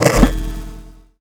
sci-fi_spark_electric_device_hum_01.wav